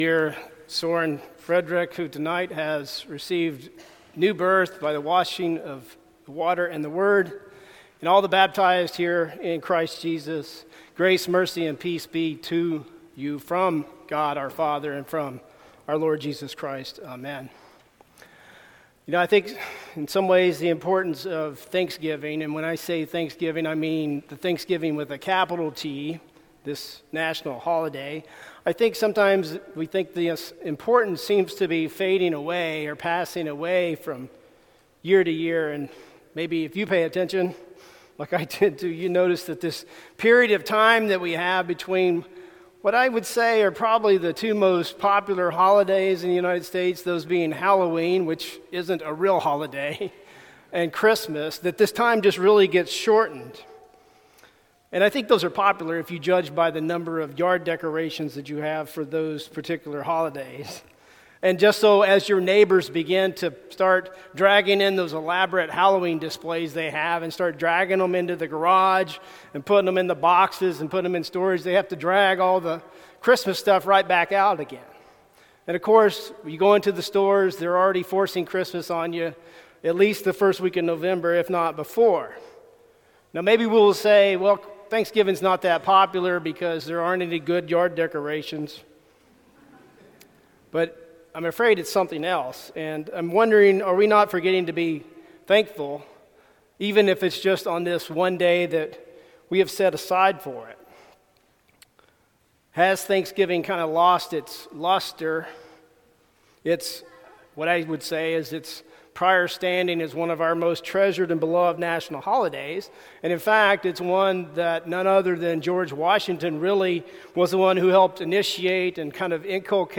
Sermon for Thanksgiving Eve